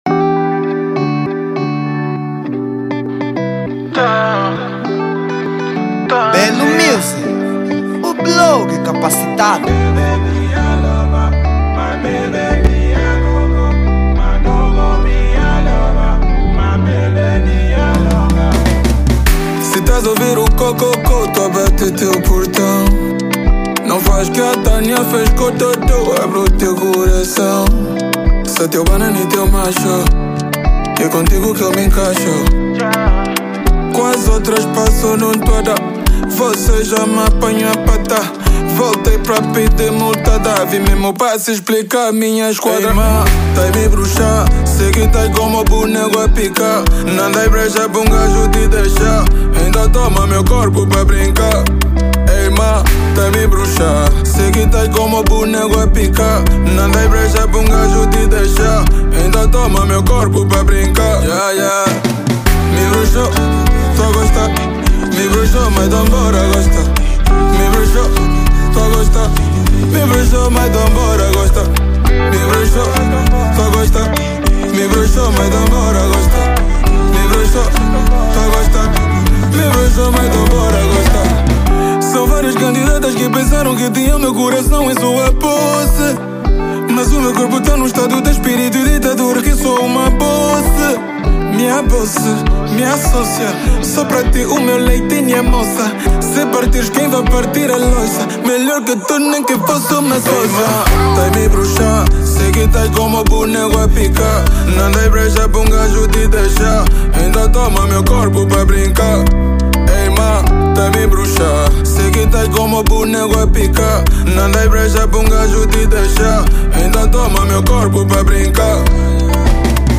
MP3 Género: Kizomba Ano de...